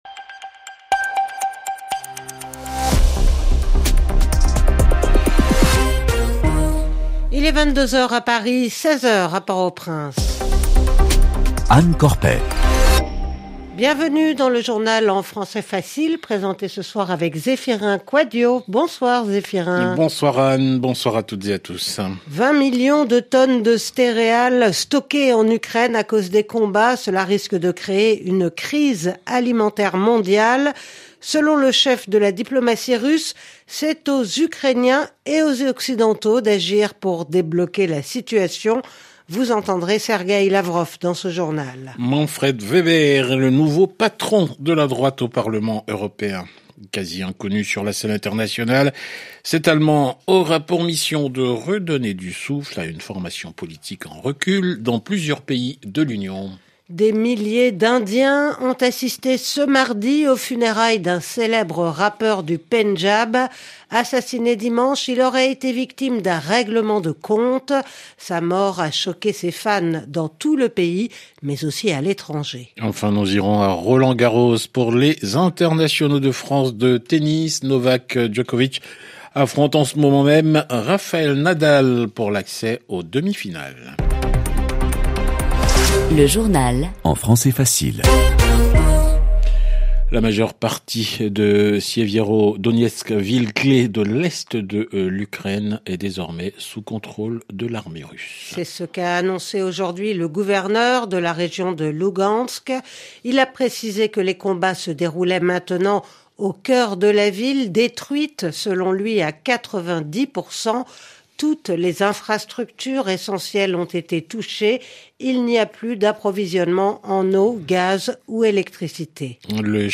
Journal en français facile